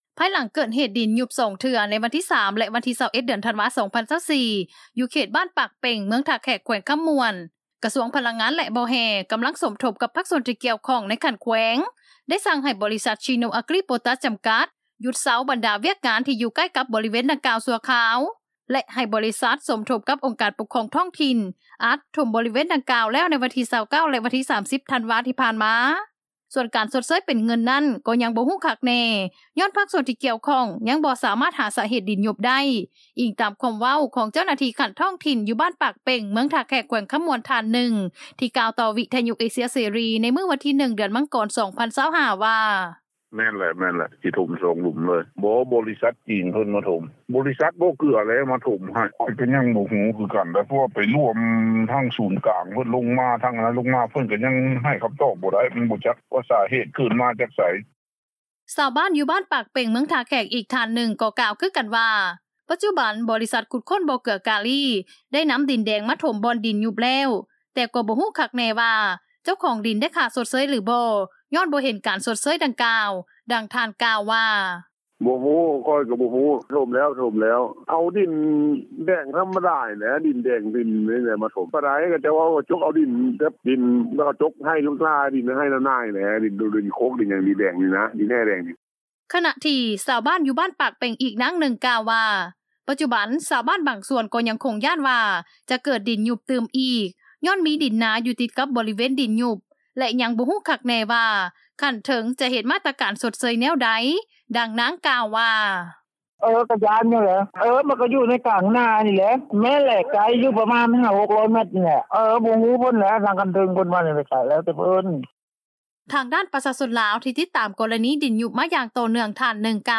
ສ່ວນການຊົດເຊີຍ ເປັນເງິນນັ້ນ, ກໍຍັງບໍ່ຮູ້ຄັກແນ່ ຍ້ອນພາກສ່ວນທີ່ກ່ຽວຂ້ອງ ຍັງບໍ່ສາມາດ ຫາສາເຫດ ດິນຍຸບໄດ້, ອີງຕາມຄໍາເວົ້າ ຂອງເຈົ້າໜ້າທີ່ ຂັ້ນທ້ອງຖິ່ນ ຢູ່ບ້ານປາກເປັ່ງ, ເມືອງທ່າແຂກ ແຂວງຄໍາມ່ວນ ທ່ານໜຶ່ງ ທີ່ກ່າວຕໍ່ວິທຍຸ
ຊາວບ້ານ ຢູ່ບ້ານປາກເປັ່ງ, ເມືອງທ່າແຂກ ອີກທ່ານໜຶ່ງ ກໍກ່າວຄືກັນວ່າ ປັດຈຸບັນ ບໍລິສັດຂຸດຄົ້ນ ບໍ່ເກືອກາລີ ໄດ້ນໍາດິນແດງ ມາຖົມ ບ່ອນດິນຍຸບແລ້ວ, ແຕ່ກໍ່ບໍ່ຮູ້ຄັກແນ່ວ່າ ເຈົ້າຂອງດິນ ໄດ້ຄ່າຊົດເຊີຍ ຫຼືບໍ່ ຍ້ອນບໍ່ເຫັນ ການຊົດເຊີຍດັ່ງກ່າວ.